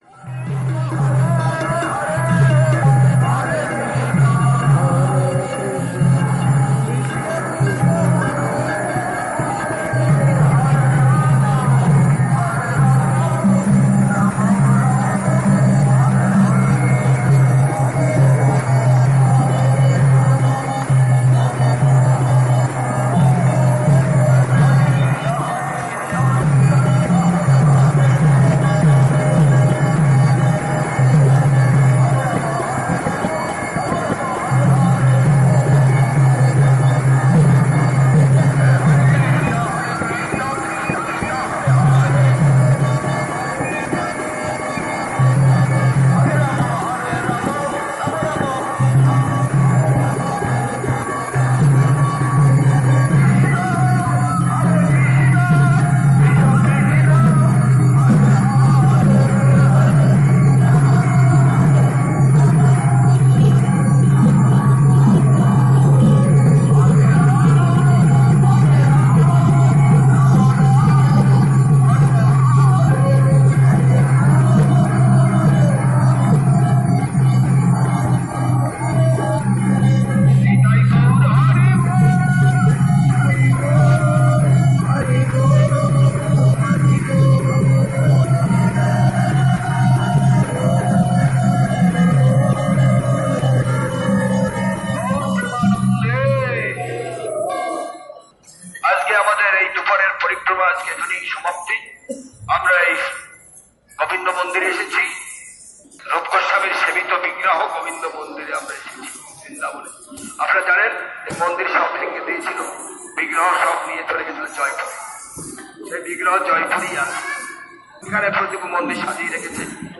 Sri Vrindavan Dham parikrama,